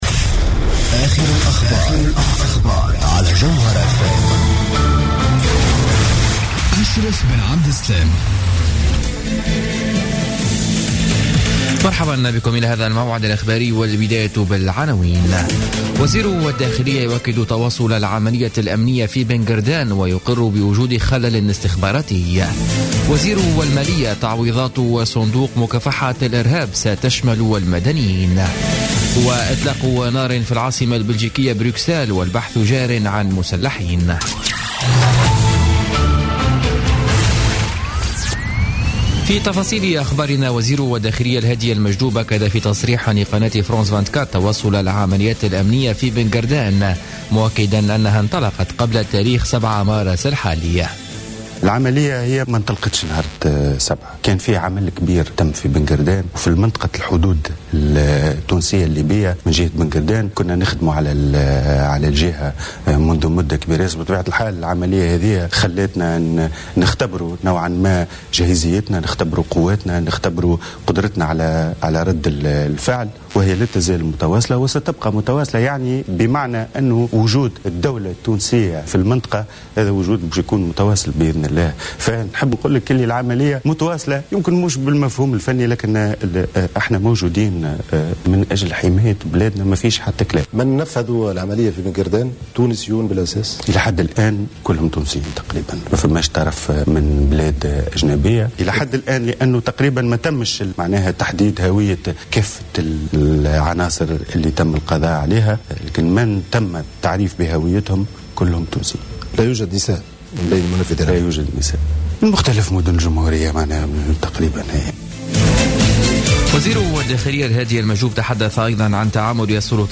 نشرة أخبار السابعة مساء ليوم الثلاثاء 15 فيفري 2016